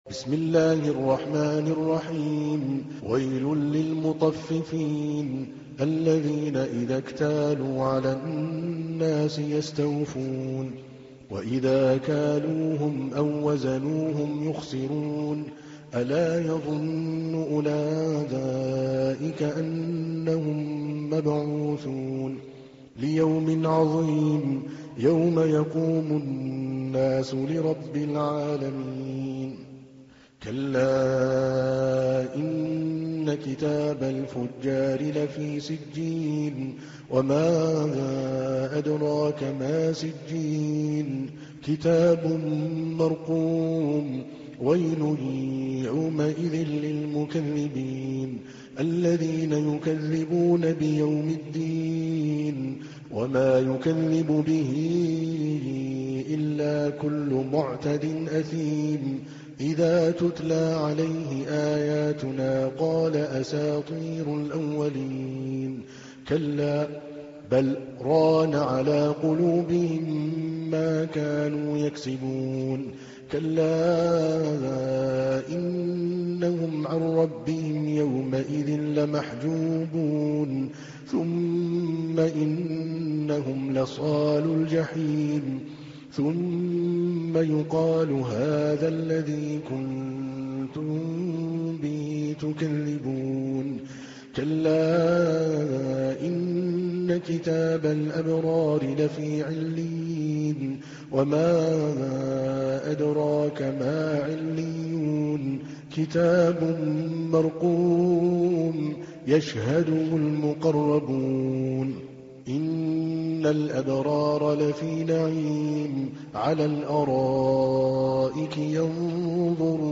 تحميل : 83. سورة المطففين / القارئ عادل الكلباني / القرآن الكريم / موقع يا حسين